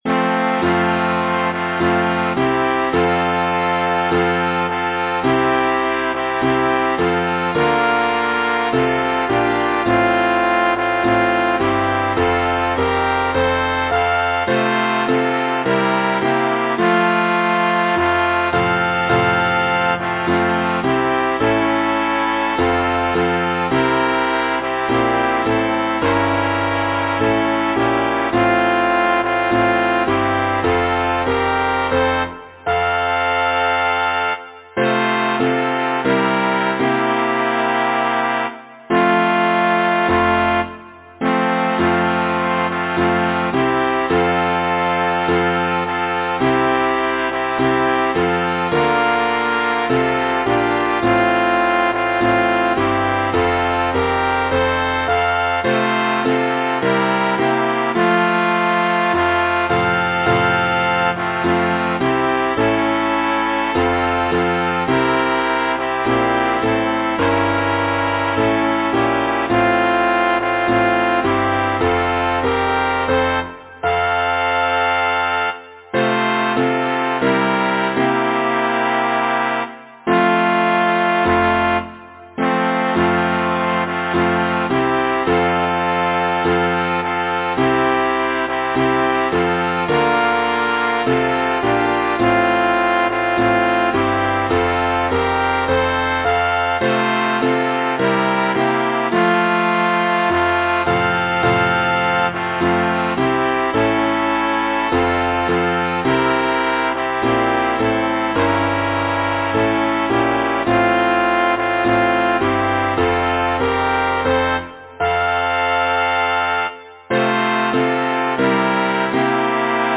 Title: The time I’ve lost in wooing Composer: Anonymous (Traditional) Arranger: Michael William Balfe Lyricist: Thomas Moore Number of voices: 4vv Voicing: SATB Genre: Secular, Partsong, Folksong
Language: English Instruments: Piano